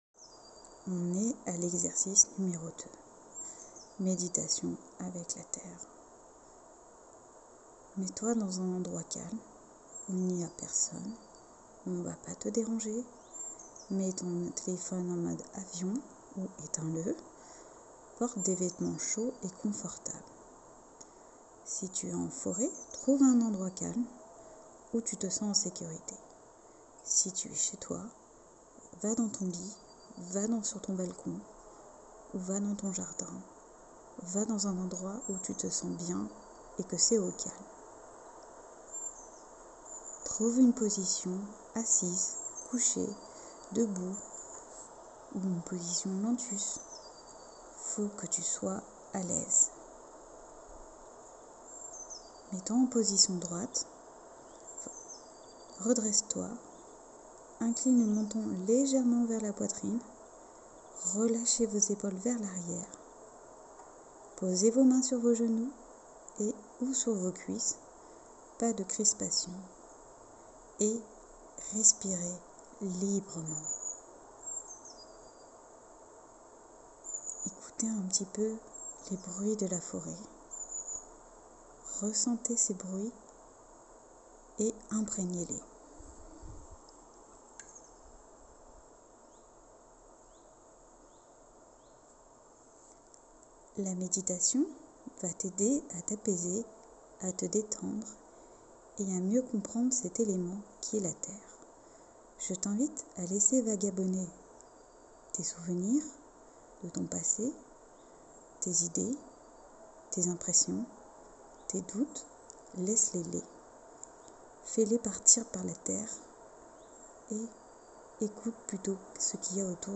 Exercice 2: Méditation
meditation-elements-terre.mp3